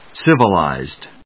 /sívəlὰɪzd(米国英語)/